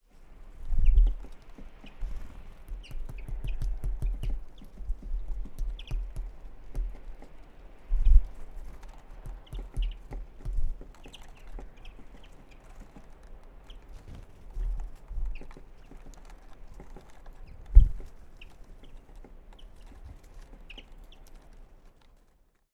101219, microphone attack by a Great Tit Parus major